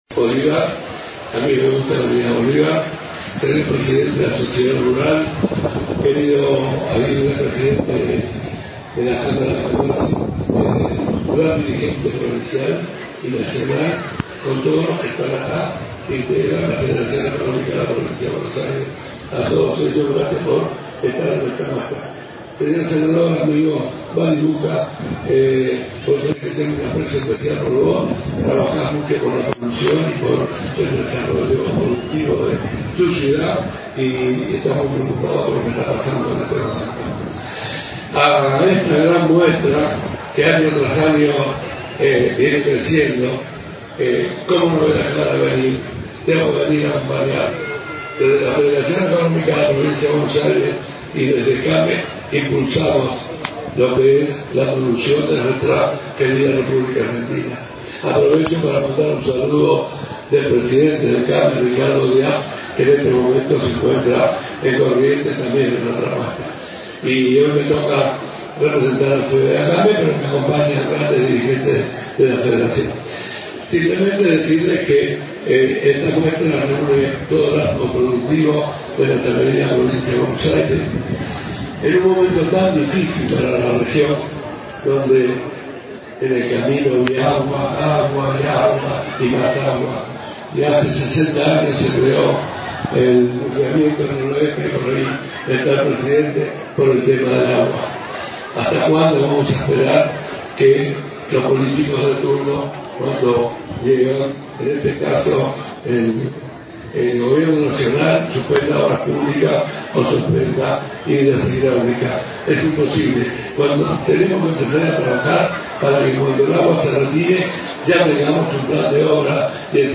Se Inauguró El Sector Comercial En La Exposición Rural de Bolívar 2025
Discursos: